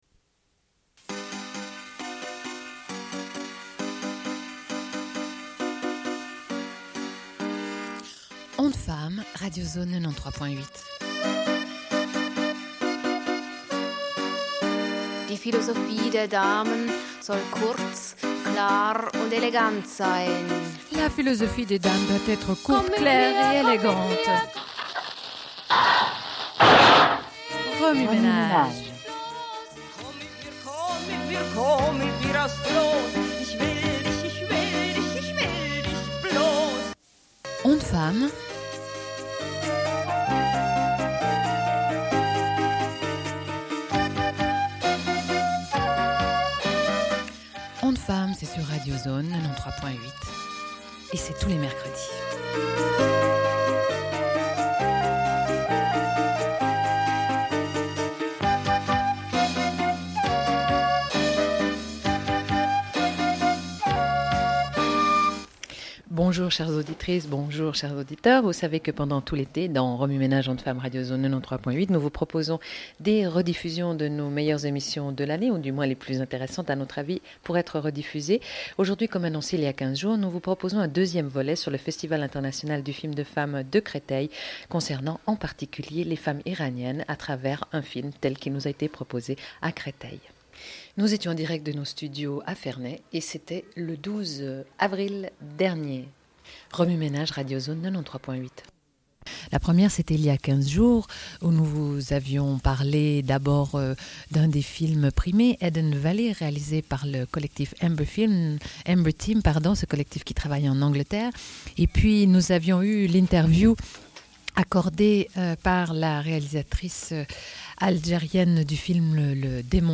Une cassette audio, face A30:51